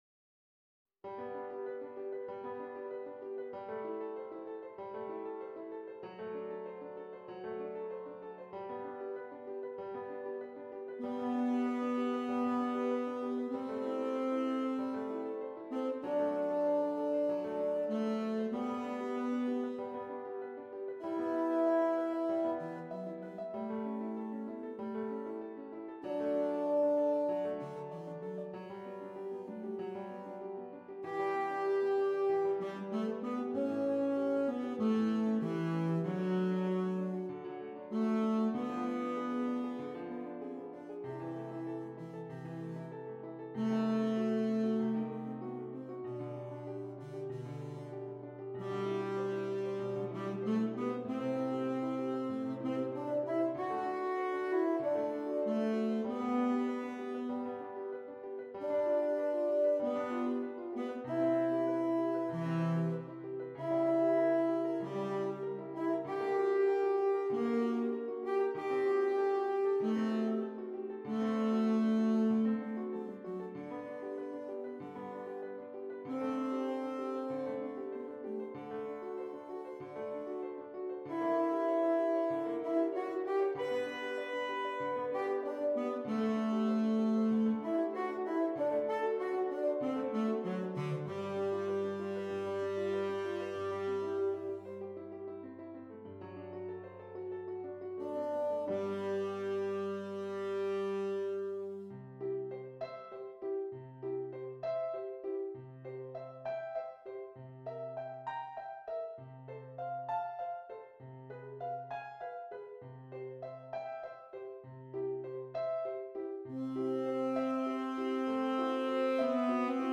Tenor Saxophone and Keyboard
tenor saxophone and keyboard (piano or organ)